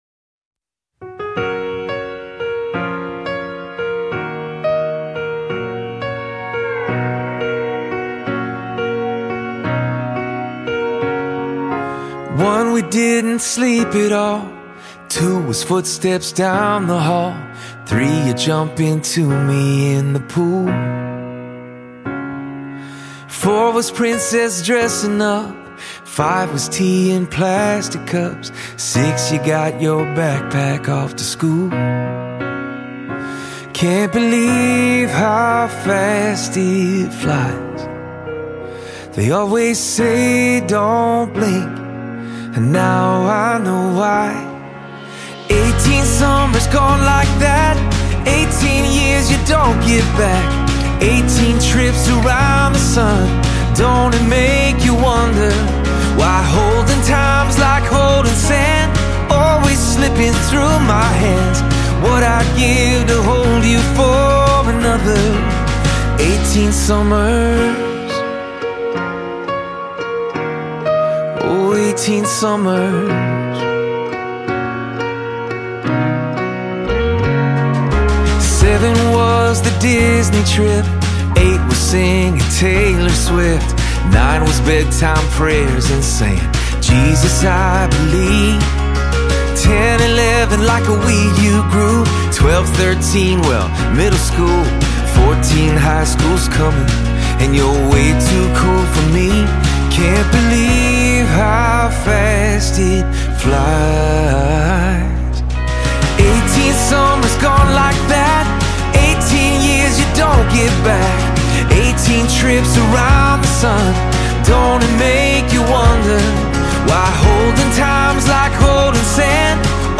gospel song
contemporary Christian
soul lifting gospel song